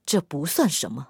SU-122A小破语音1.OGG